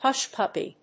音節húsh pùppy